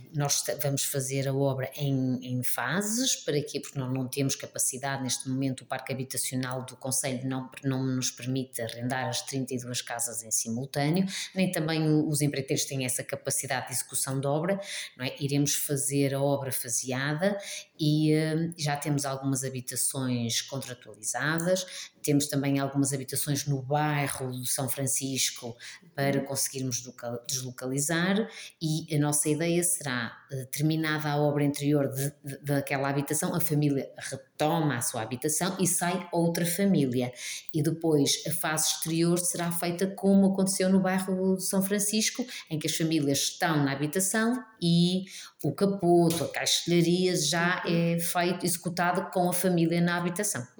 A obra vai decorrer em vários momentos. Um primeiro com a deslocação das famílias para outras casas disponibilizadas a cargo do município, enquanto duram as obras no interior, e uma outra numa fase posterior, como acrescenta a autarca: